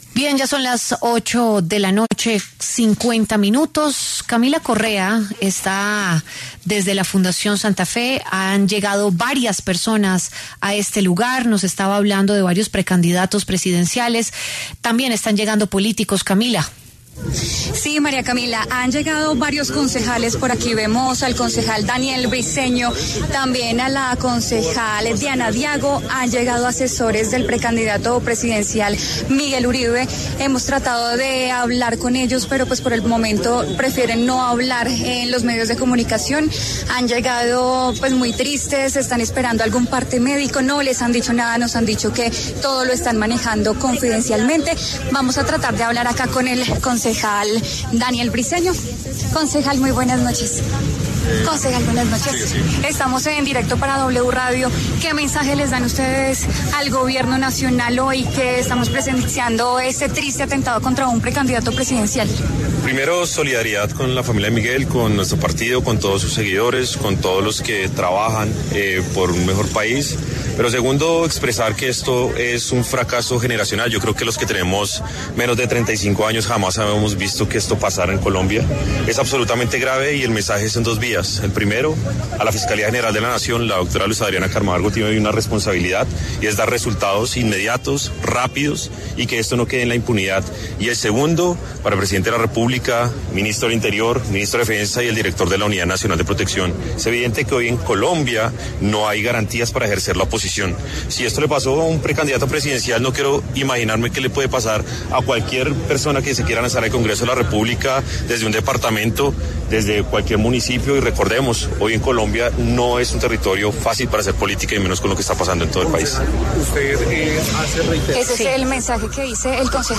Al respecto, el concejal de Bogotá Daniel Briceño, también miembro del Centro Democrático, habló en W Radio para ofrecer su perspectiva sobre el tema.